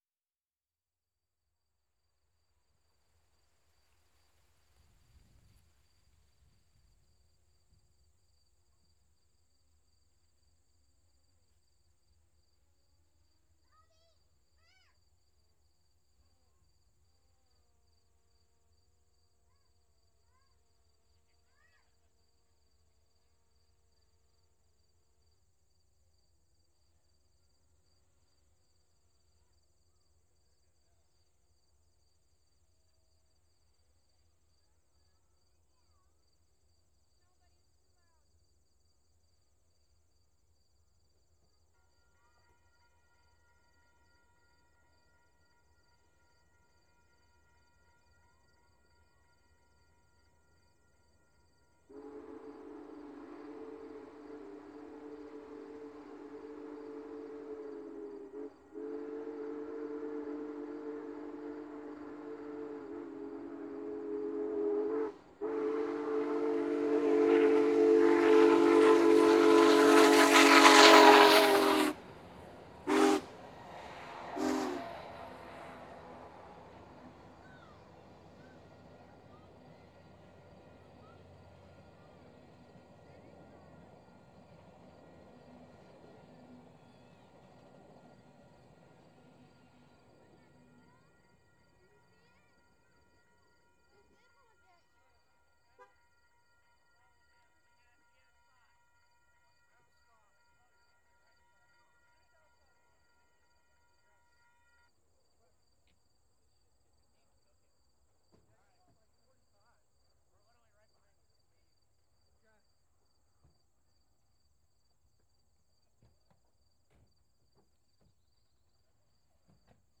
Recorded with Tascam DR-40X on a tripod about 200 feet from the "V" Avenue crossing.
WARNING: extreme dynamic range. No limiting or compression has been applied. If you turn the volume up at the beginning because it seems too quiet, you might very well hurt your ears (or speakers) later because the steam horn is so loud when it comes through. We are facing north; the train is eastbound, so moving from our left to right.
I've come to use the low-cut filter on the Tascam as a matter of habit when recording outdoors, to reduce wind noise.
The world's largest steam locomotive is absolutely barrelling by, but in the recording it screams when it should also thunder.